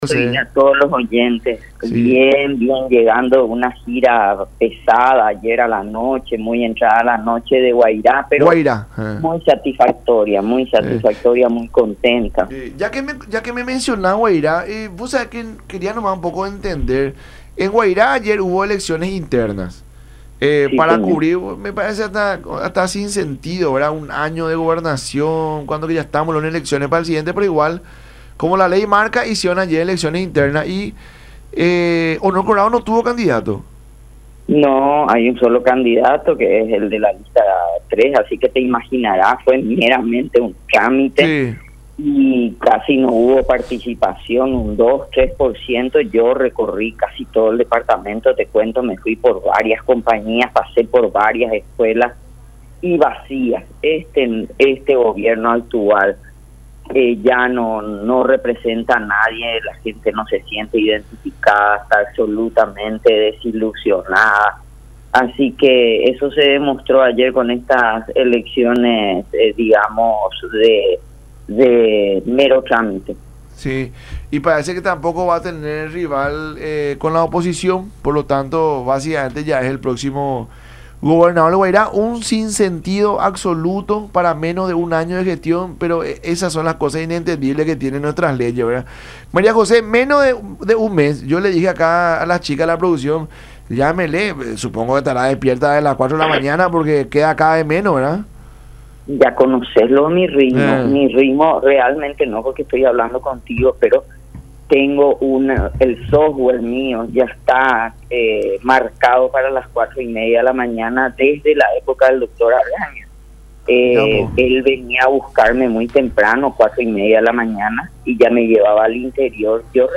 La gente no se siente identificada y está absolutamente desilusionada”, dijo Argaña en contacto con La Mañana De Unión por Unión TV y radio La Unión.